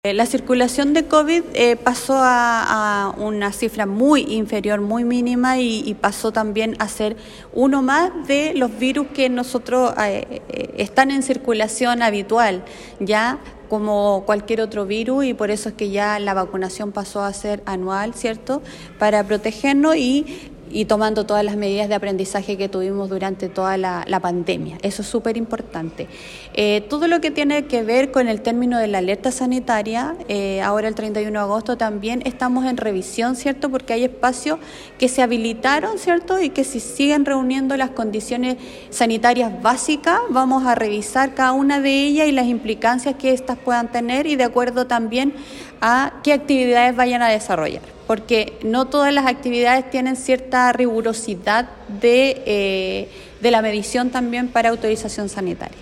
En cuanto a la circulación del SarsCov-2, la Seremi de Salud señaló que paso a ser una cifra mínima, que se suma a los habituales virus que médicamente se atendían durante el invierno.